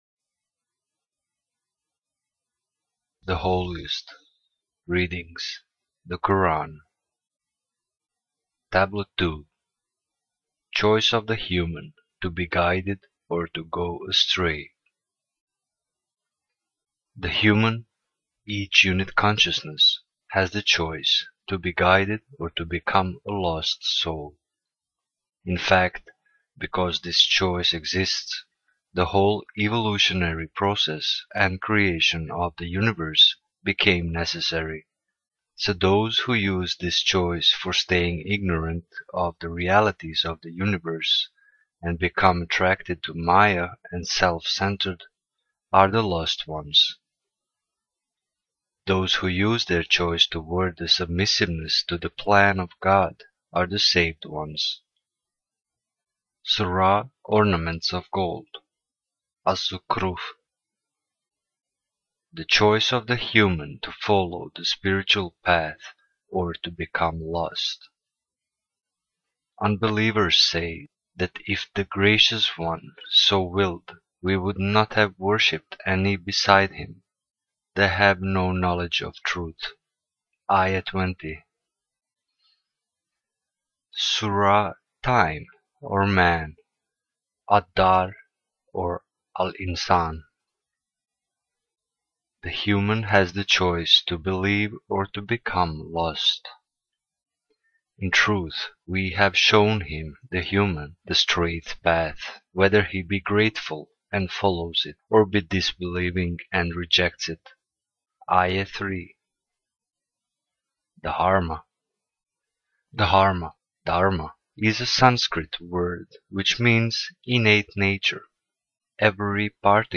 THE HOLIEST Readings (The Koran)